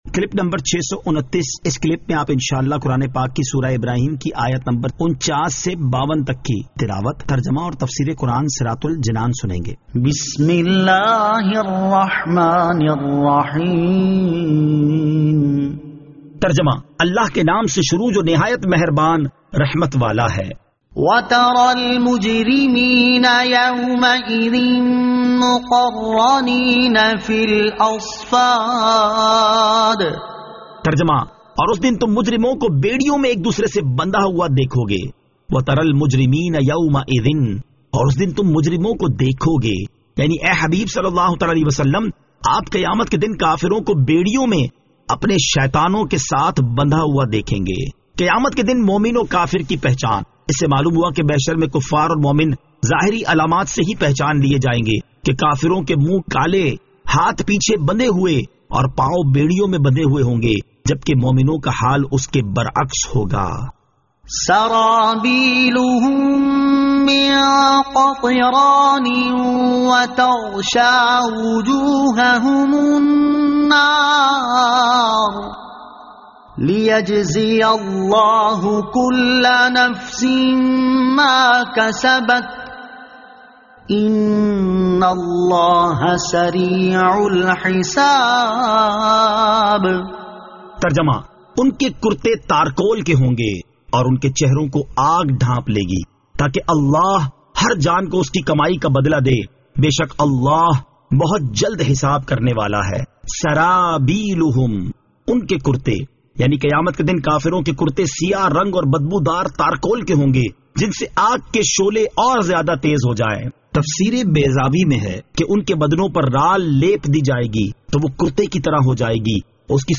Surah Ibrahim Ayat 49 To 52 Tilawat , Tarjama , Tafseer